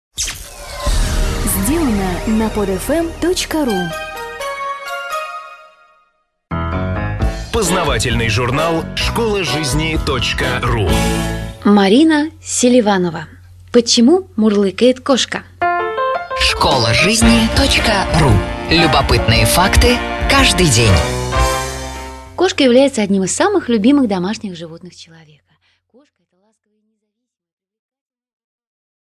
Аудиокнига Почему мурлыкает кошка?